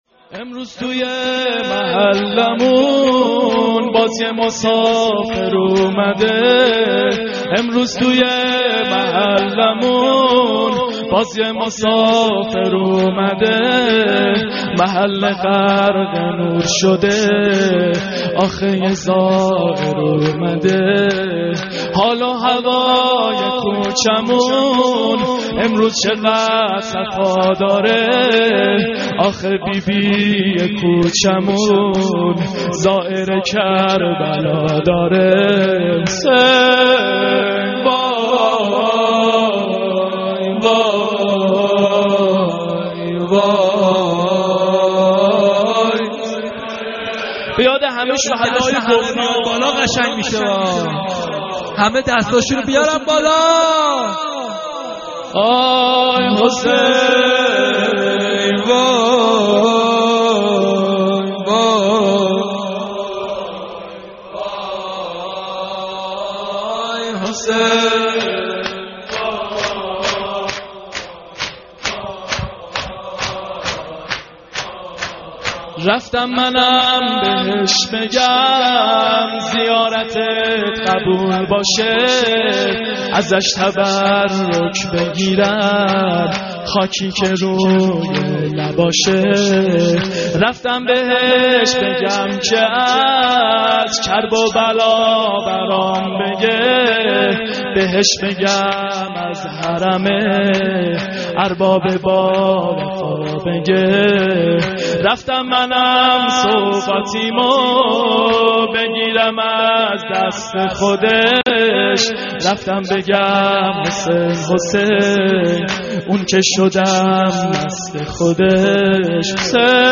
maddahi-238.mp3